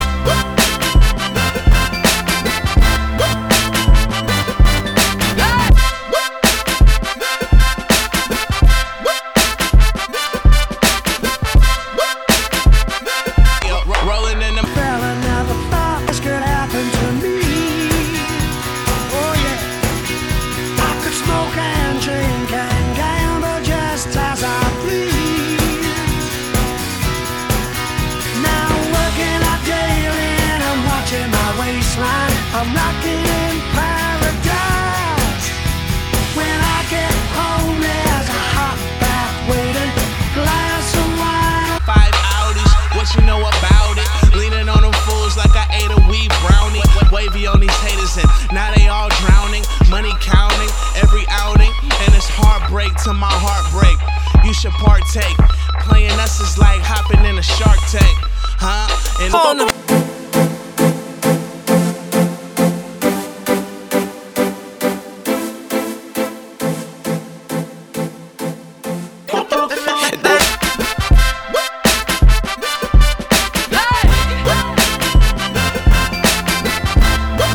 Indie